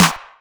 pdh_snare_one_shot_synthetic_beef.wav